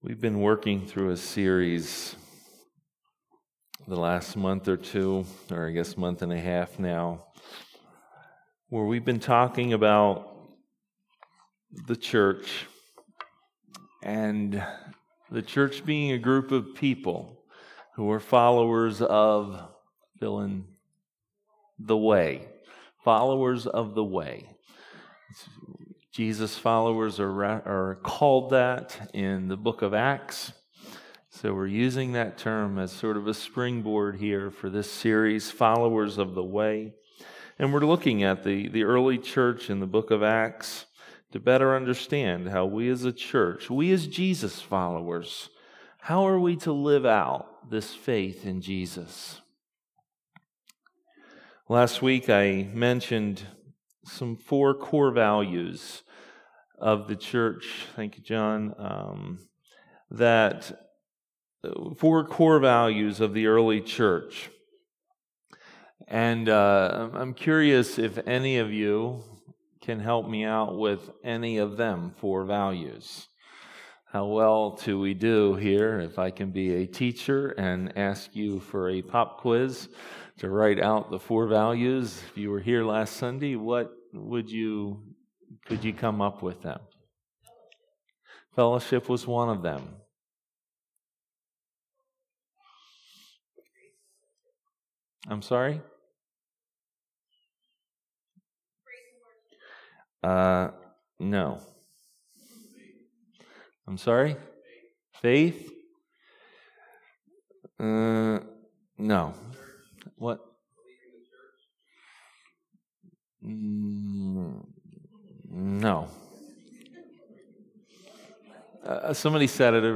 Maranatha Fellowship's Sunday Morning sermon recordings.